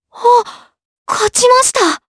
Leo-Vox_Victory_jp.wav